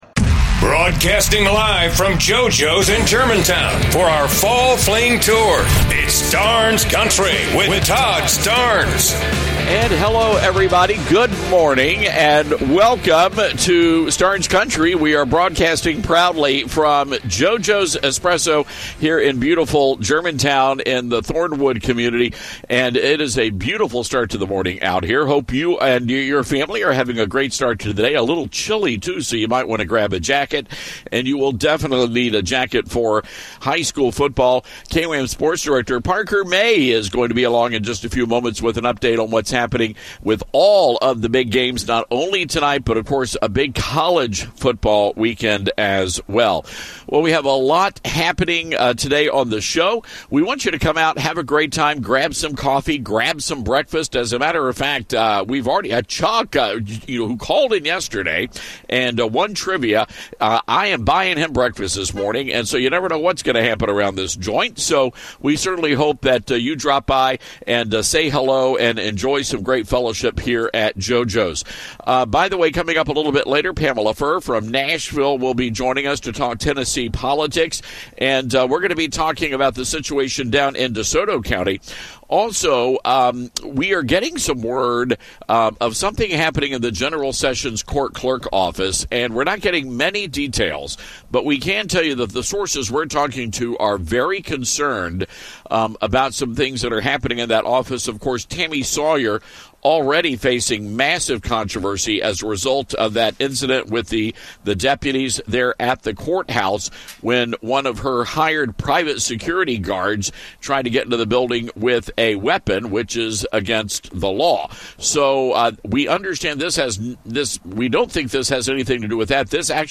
Broadcasting Live from JoJo's Espresso in Germantown!